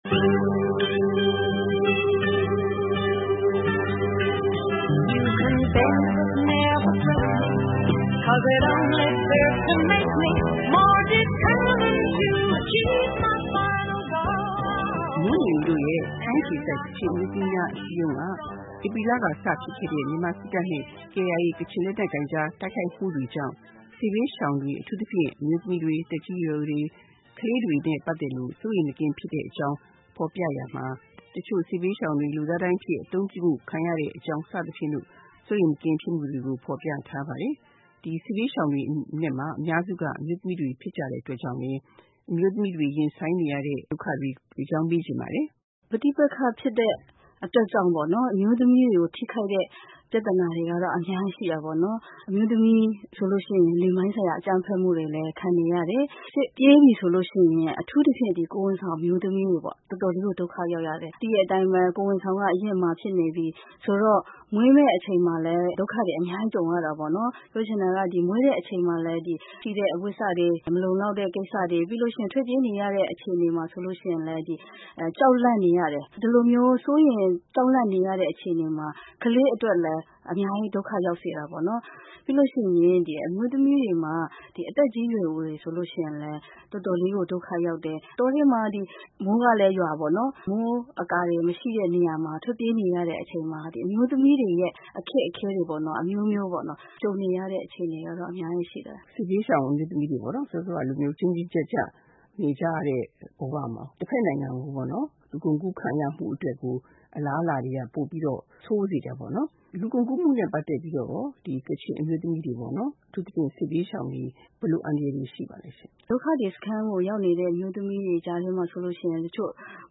တွေ့ဆုံမေးမြန်းထားတာကို ဒီသီတင်းပတ်အမျိုးသမီးကဏ္ဍမှာ တင်ဆက်ထားပါတယ်။